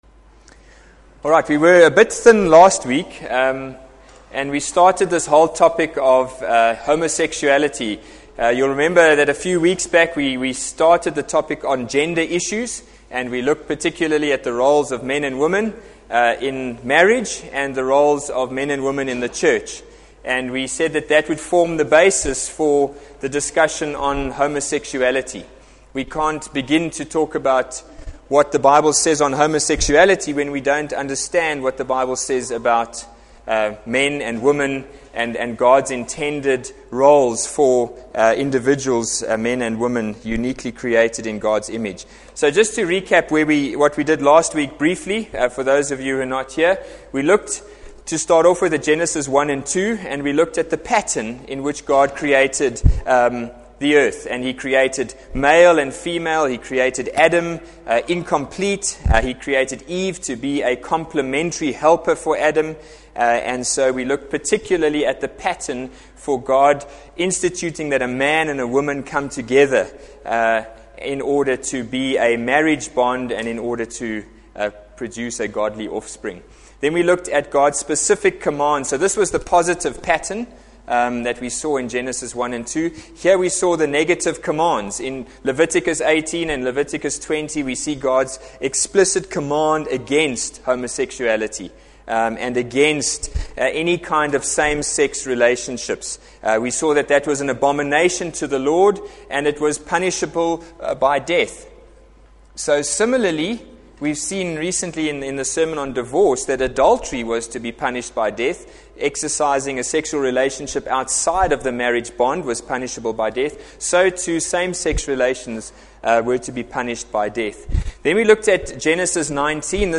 Adult Bible Class - Homosexuality - Part 2.mp3